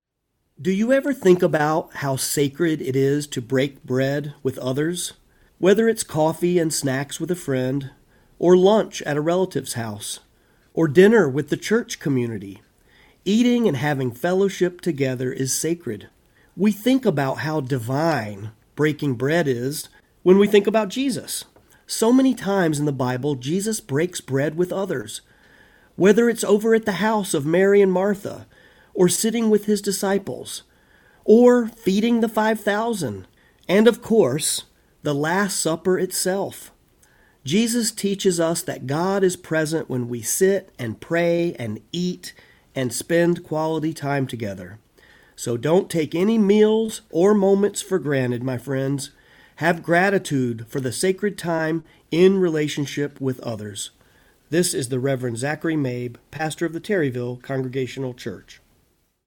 One Minute inspirational thoughts presented by various clergy!